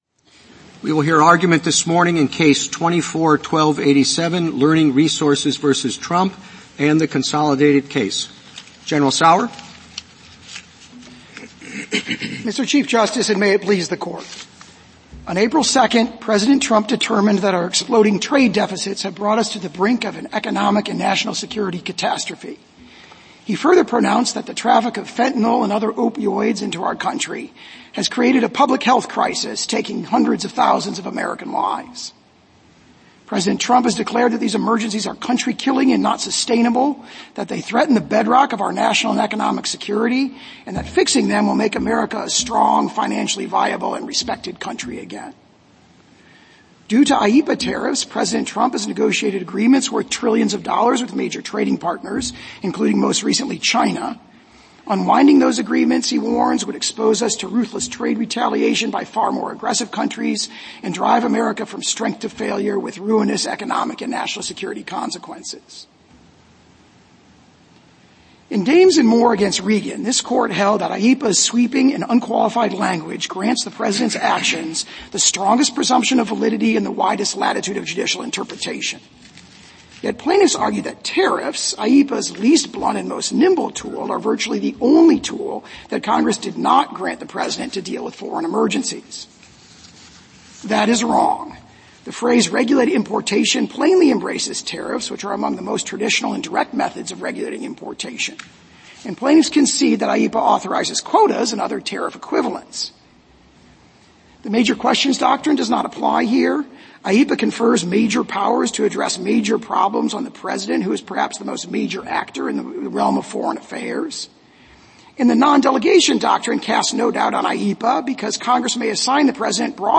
U.S. Supreme Court Oral Arguments Learning Resources, Inc. v. Trump Play episode November 5 2h 39m Bookmarks View Transcript Episode Description A case in which the Court will decide whether the International Emergency Powers Act, 50 U.S.C. § 1701 (“IEEPA”), permits the president to impose tariffs.